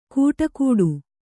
♪ kūṭakūḍu